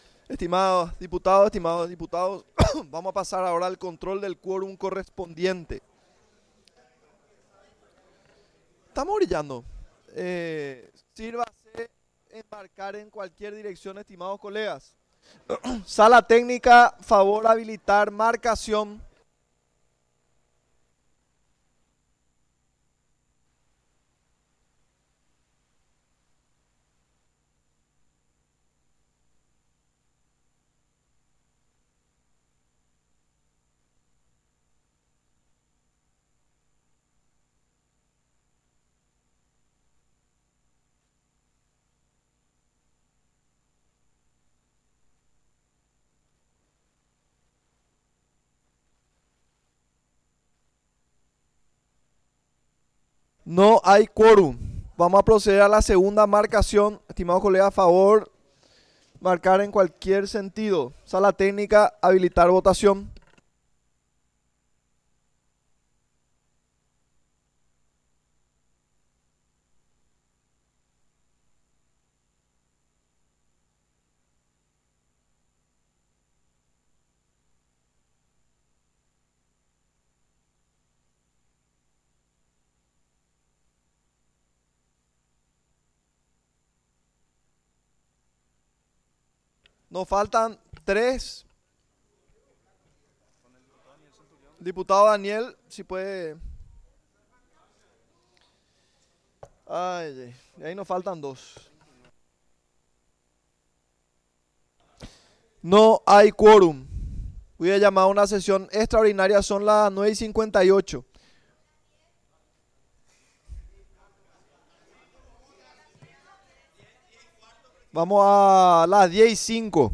Sesión Ordinaria, 26 de agosto de 2025